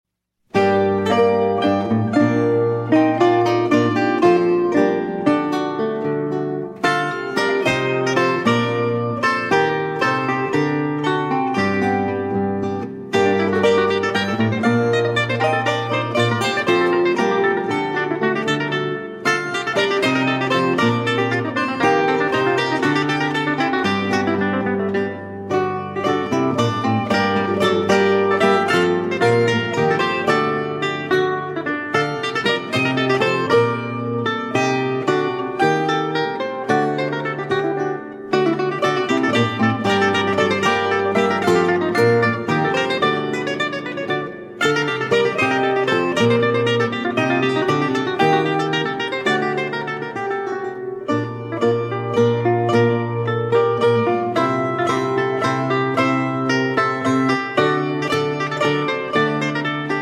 Lute and Theorbo